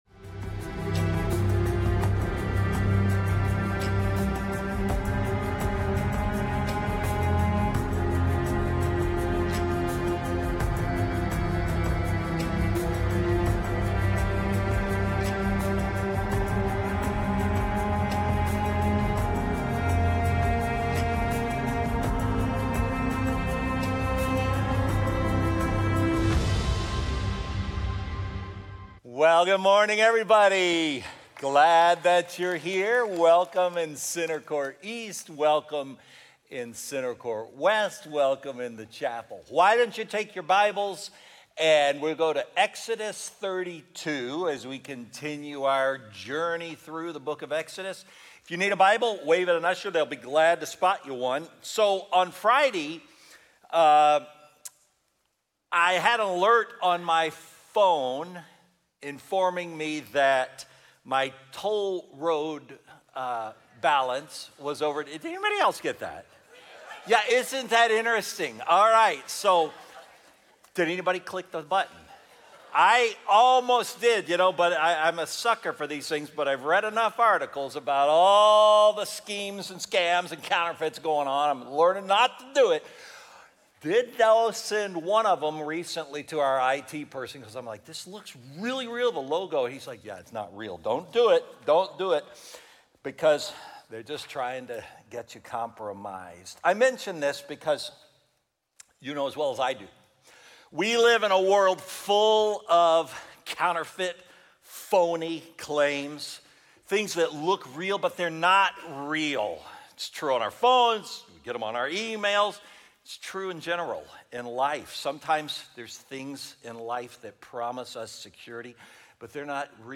Weekly biblically-based sermons from Faithbridge church in Spring, Texas.